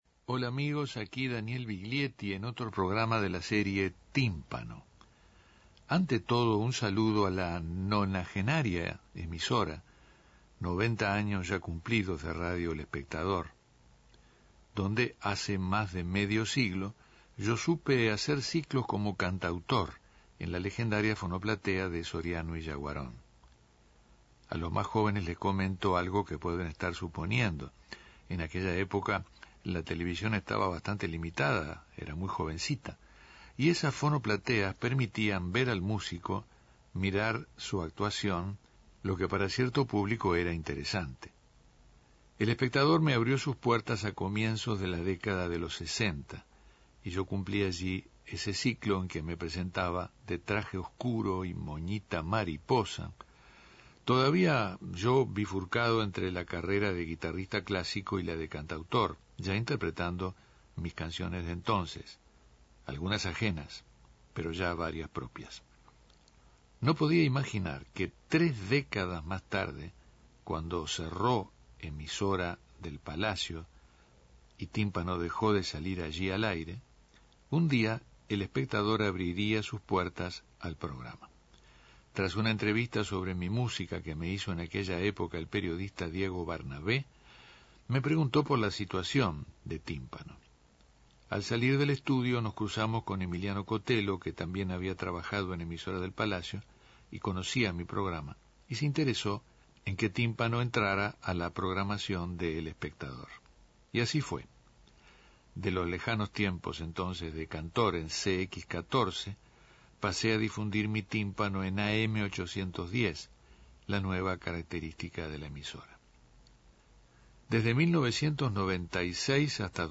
Diálogo de fin de año con Eduardo Galeano
Tímpano aprovechó el fin de año para dialogar con el escritor uruguayo Eduardo Galeano. En medio de nuevas escrituras la charla paso por la figura de Nelson Mandela, la Venezuela de Simón Rodríguez, Argentina como su segunda casa y nuestro país, Uruguay, tierra de la tuvo que alejarse en el exilio.